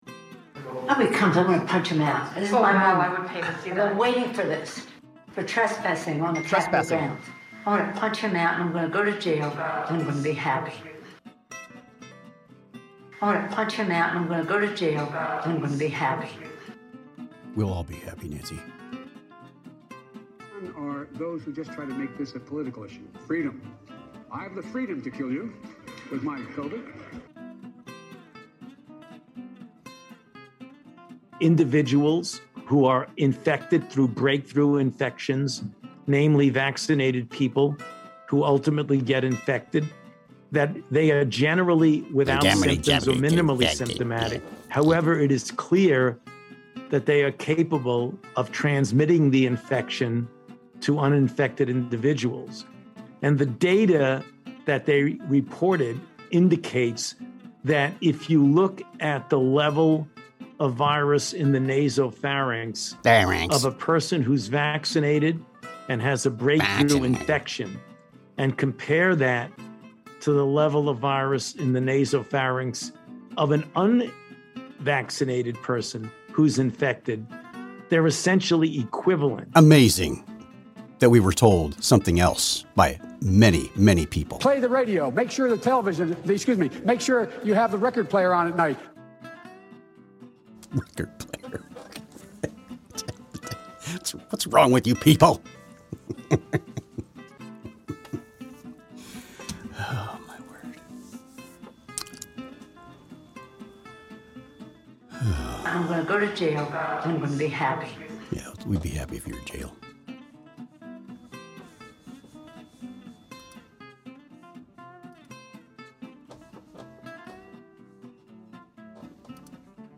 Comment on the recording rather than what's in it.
LIVE SHOW on Thunder Thursday. Oil still in the news. Live callers. The News According to Me!